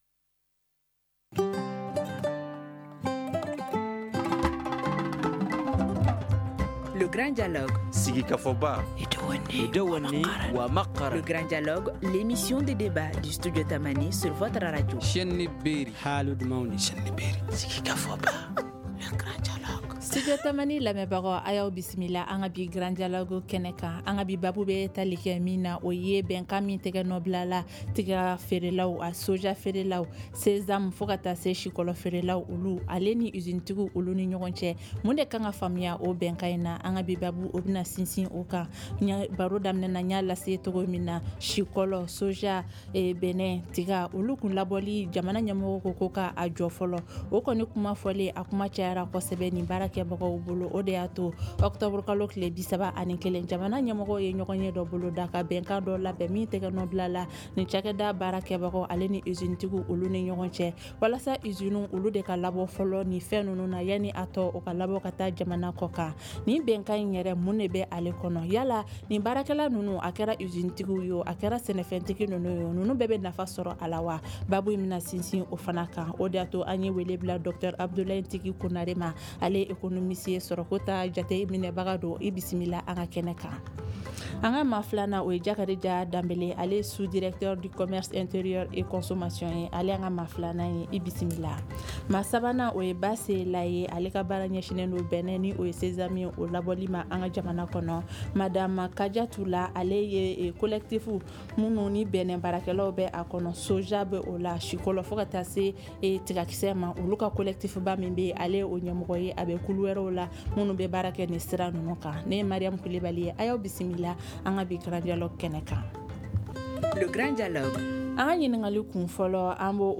NOS INVITES